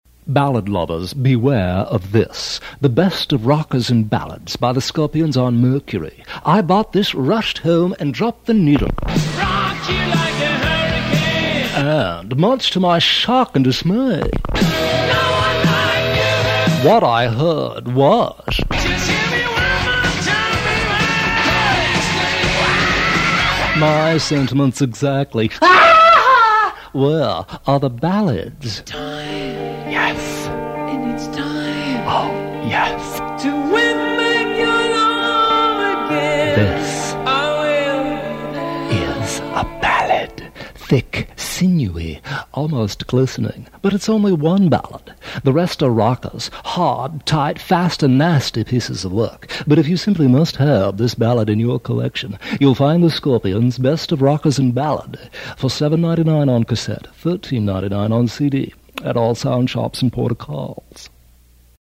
Side A: Promos/Sweepers